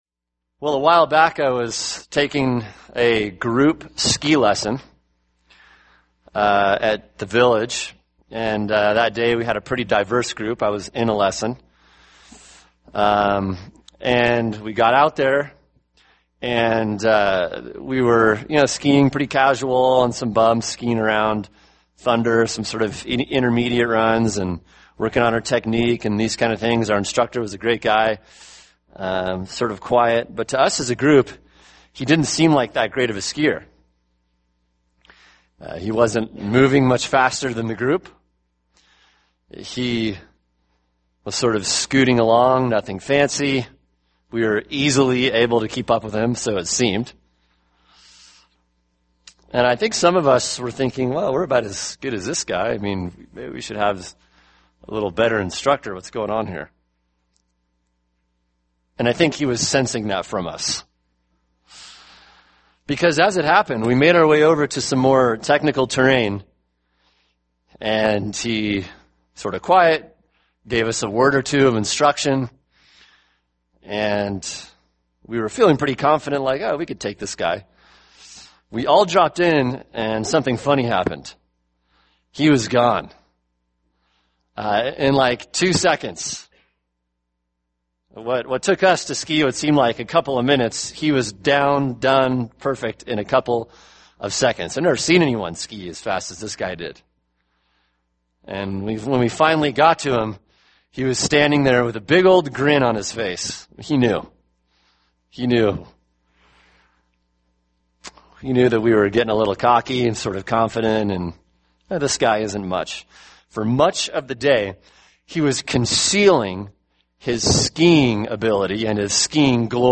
[sermon] Matthew 17:1-13 – The Glory of Christ | Cornerstone Church - Jackson Hole